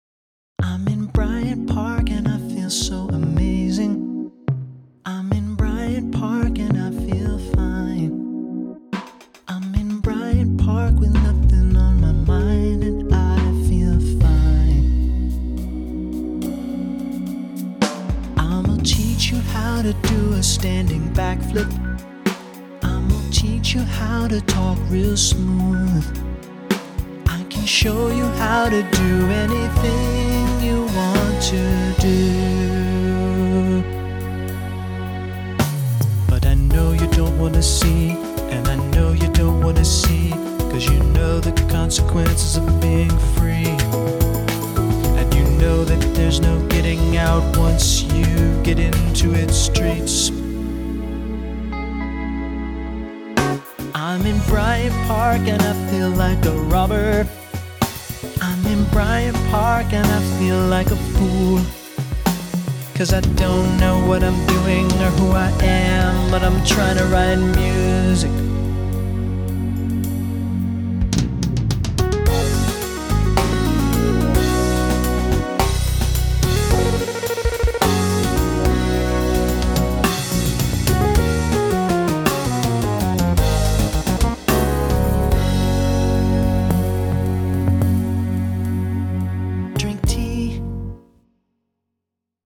BPM108
Audio QualityPerfect (High Quality)
A pretty chill tune